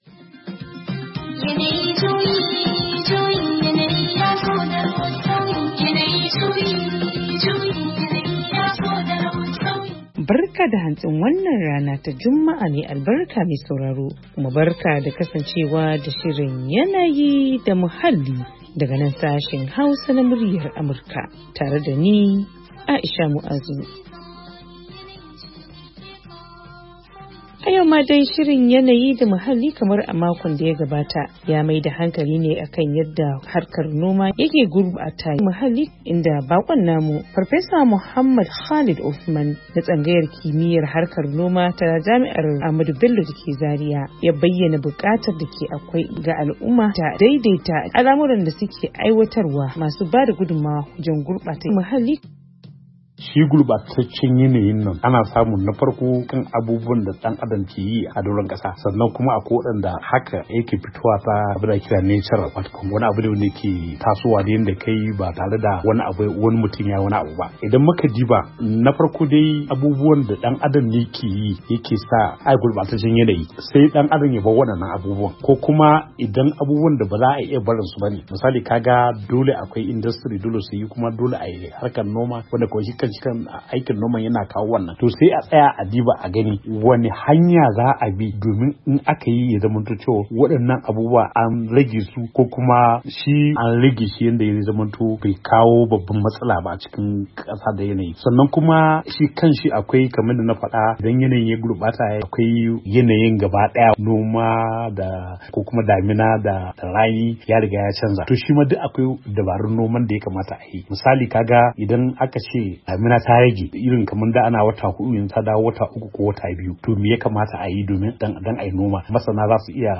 A shirin na wannan makon, mun kawo kashi na biyu na tattaunawa kan yadda noma ke ba da gudummawa wajen gurbata muhalli a Najeriya.